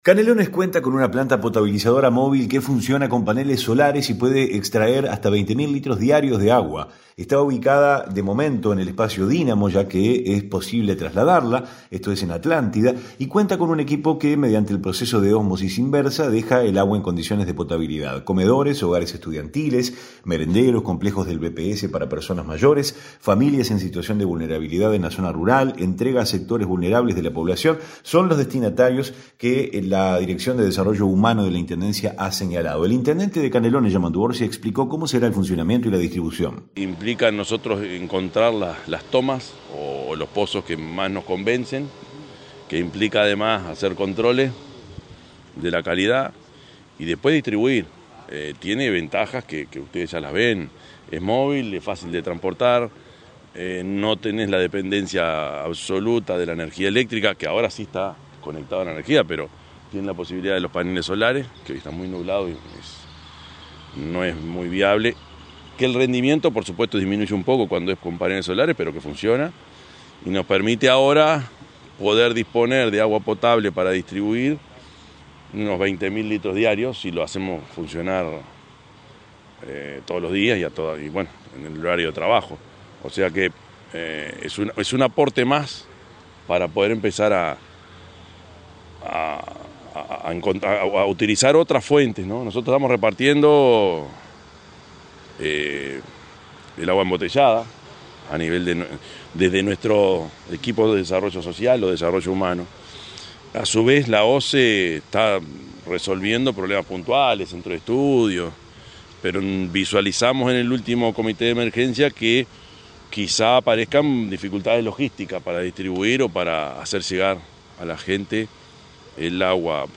REPORTE-POTABILIZADORA.mp3